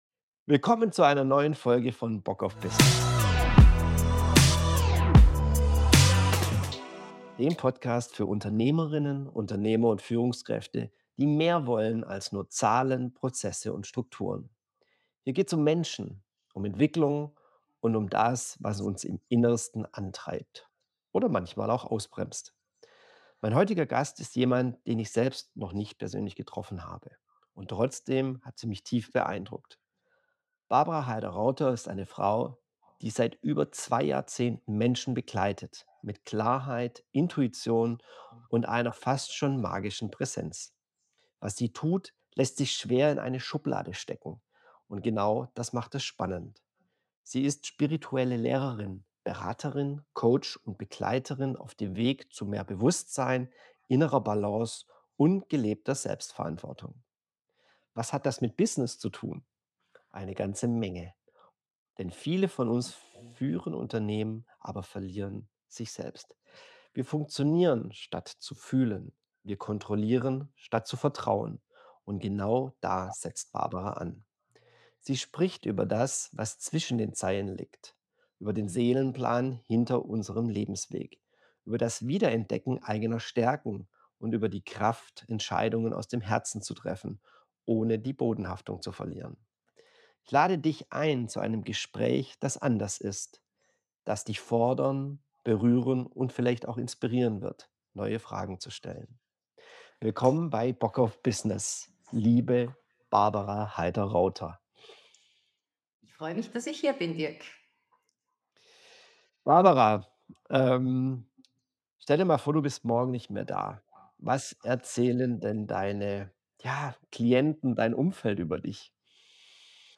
Warum Spiritualität kein Widerspruch zu Business ist, sondern eine vergessene Ressource Ich verspreche dir: Dieses Gespräch ist anders.